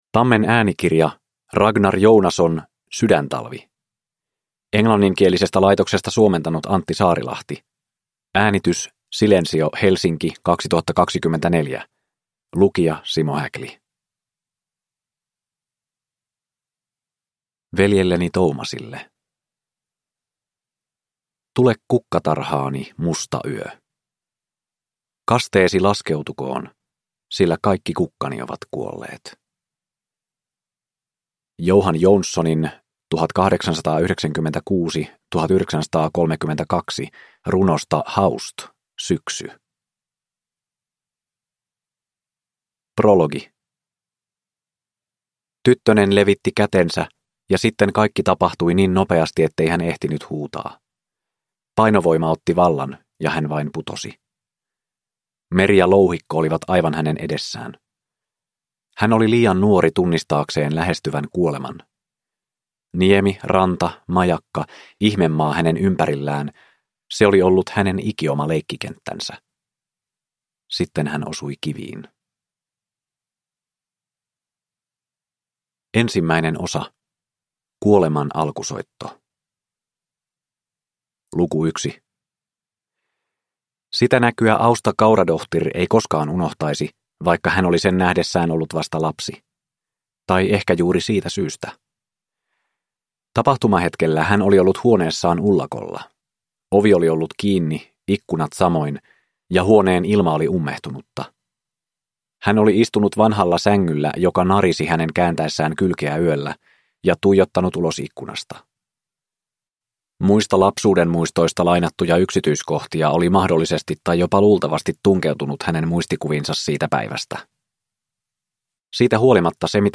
Sydäntalvi – Ljudbok